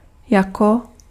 Ääntäminen
IPA : /laɪk/